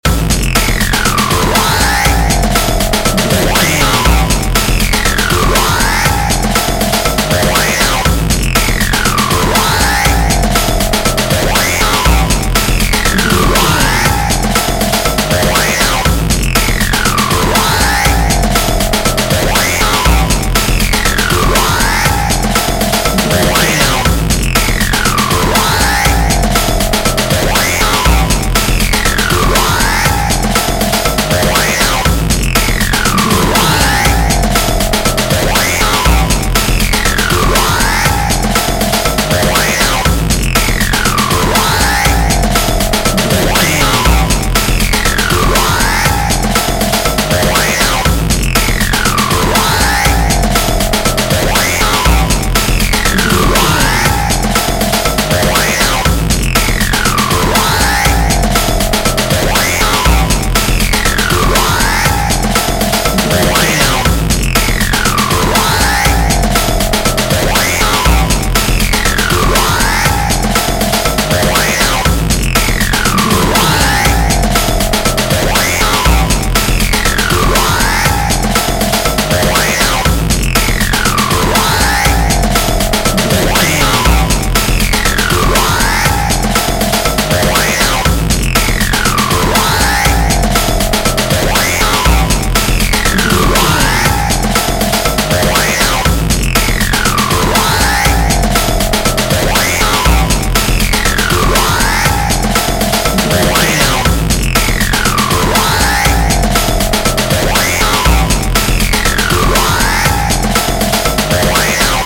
Electronic Dub Music Cue.
Fast paced
Atonal
Fast
aggressive
dark
driving
energetic
disturbing
drum machine
synthesiser
Drum and bass
break beat
sub bass
industrial
synth leads
synth bass